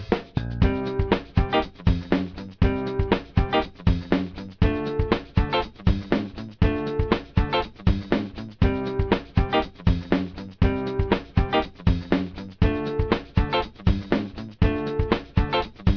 All samples are reduced quality versions of the full audio available on the CD.
Instrumental
Another play on words, and another rock song in Garageband.